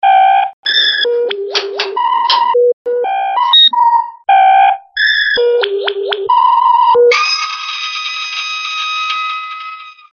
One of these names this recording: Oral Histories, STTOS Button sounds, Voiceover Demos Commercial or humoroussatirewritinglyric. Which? STTOS Button sounds